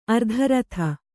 ♪ ardharatha